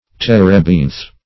Terebinth \Ter"e*binth\, n. [L. terbinthus, Gr. ?: cf. F.